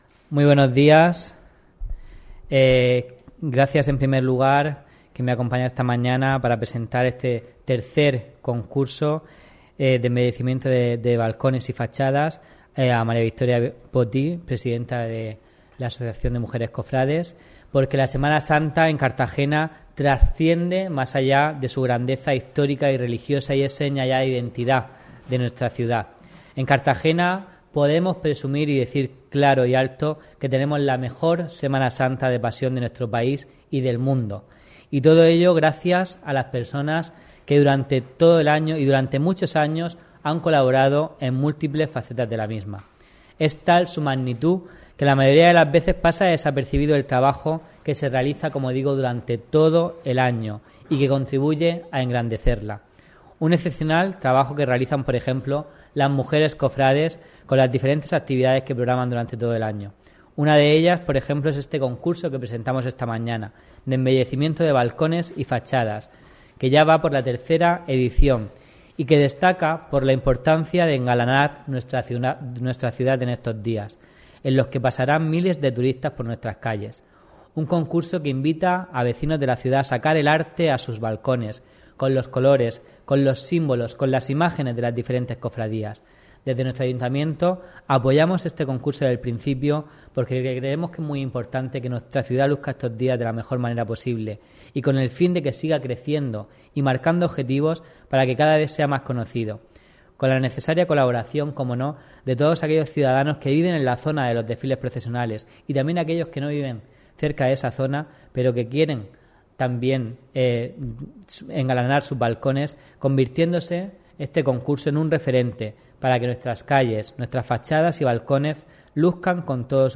Este concurso ha sido presentado por el concejal de Cultura